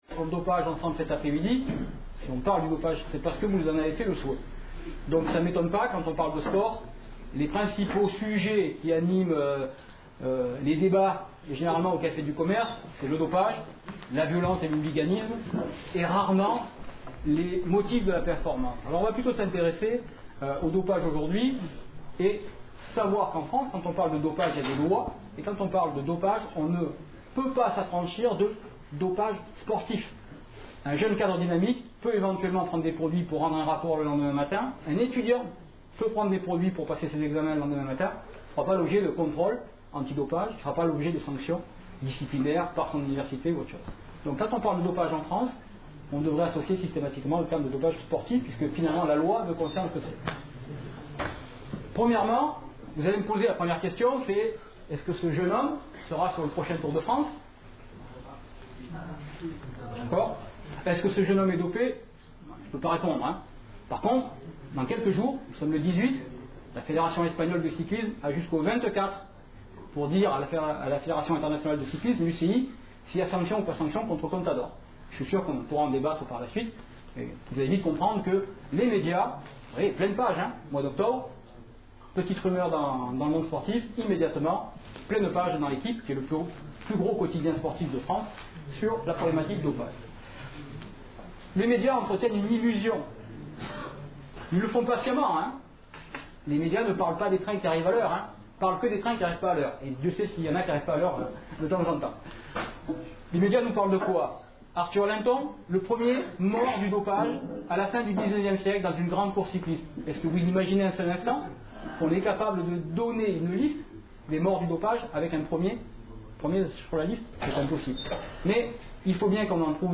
Une conférence de l'UTLS au Lycée